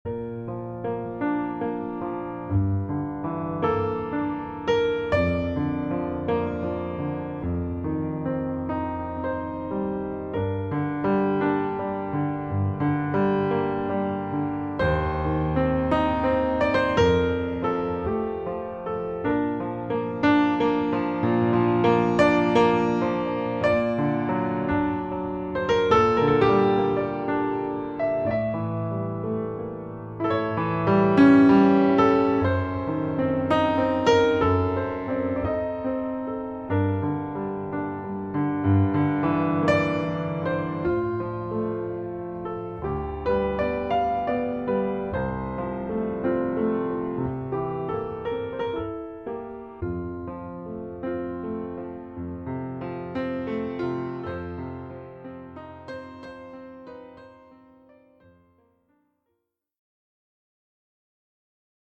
ヒーリングＣＤ
優しいピアノの音がリラックス効果を高めます。